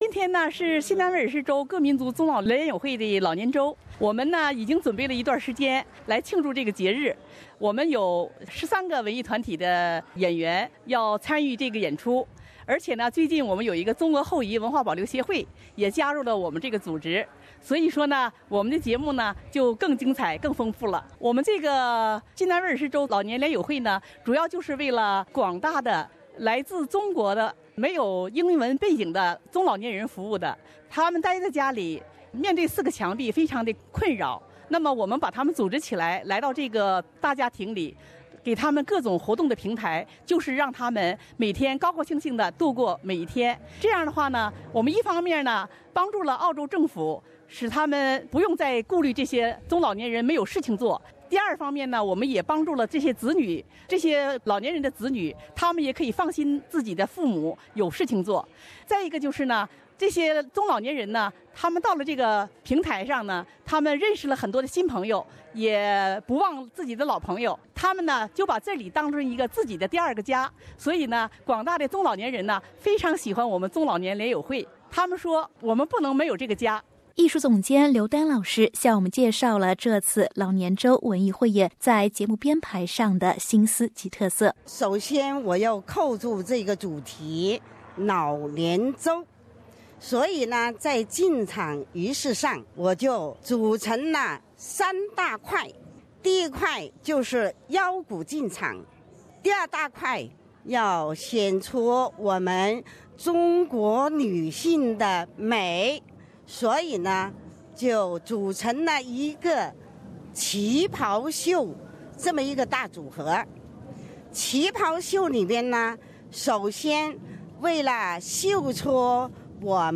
新州联友会庆祝老年周文艺演出于4月14日在Granville Town Hall 成功举办。来自悉尼的13个文艺团体与中俄后裔文化保留协会一同以最美夕阳红为主题，为生活在悉尼的数百名长者带来了民族舞、旗袍秀、小合唱、乐器演奏等精彩纷呈的演出。